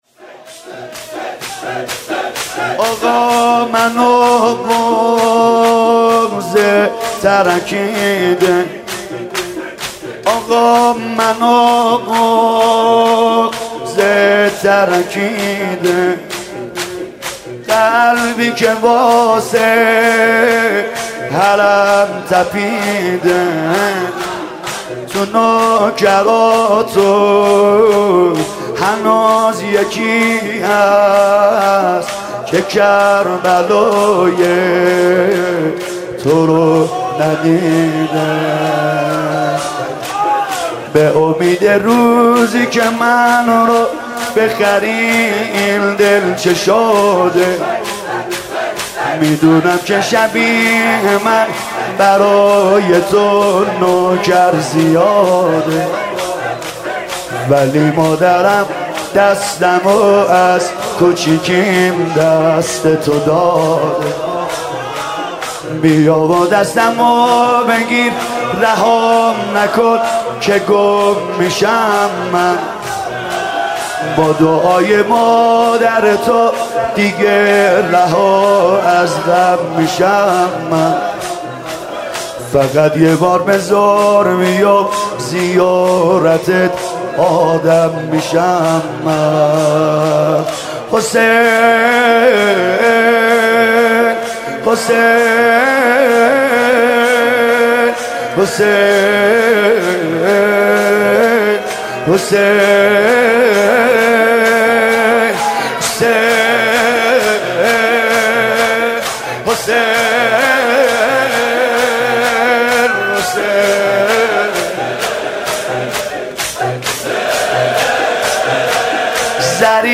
مداحی 6 متاسفانه مرورگر شما، قابیلت پخش فایل های صوتی تصویری را در قالب HTML5 دارا نمی باشد.
شور